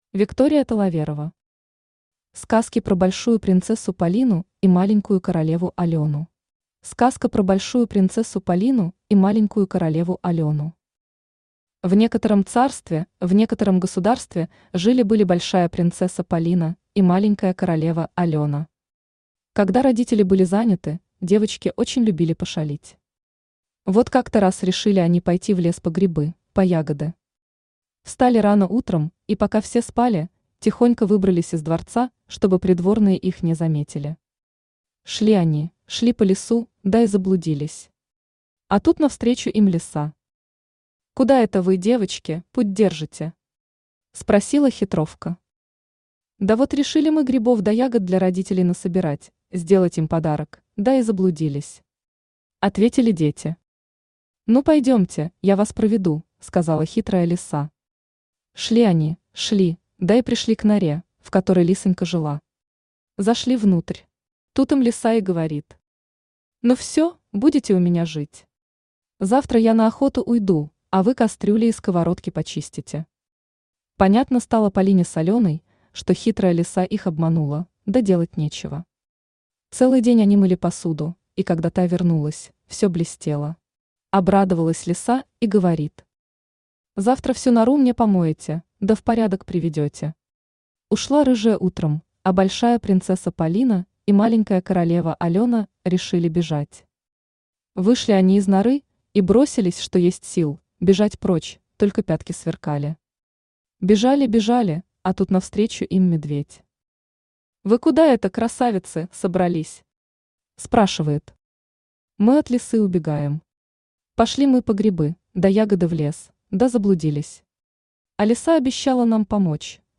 Аудиокнига Сказки про большую принцессу Полину и маленькую королеву Алёну | Библиотека аудиокниг
Читает аудиокнигу Авточтец ЛитРес.